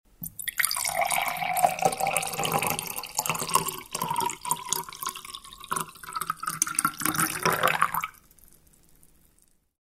Звуки наливания воды, жидкости
На этой странице собраны разнообразные звуки наливания воды и других жидкостей: от наполнения стакана до переливания напитков в высокий бокал.